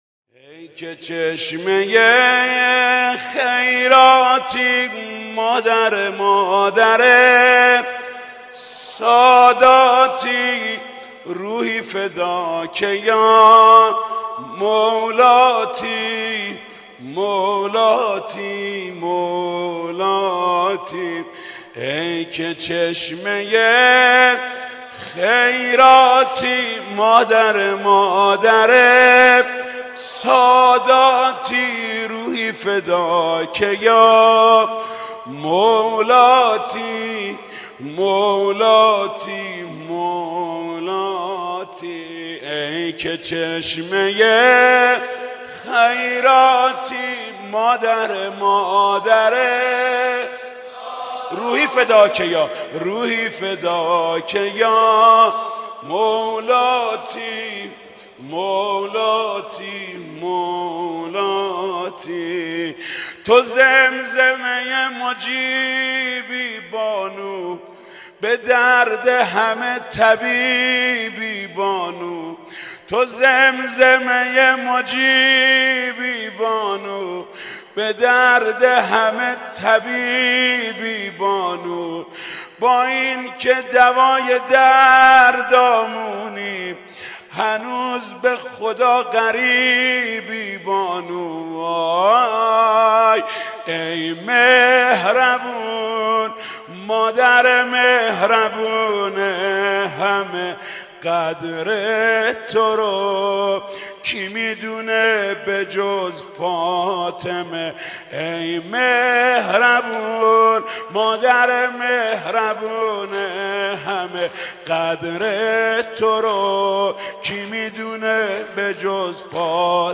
دانلود مداحی وفات حضرت خدیجه